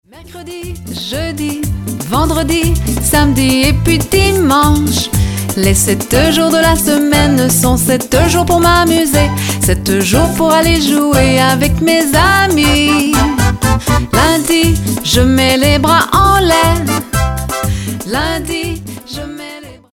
fun, action-oriented song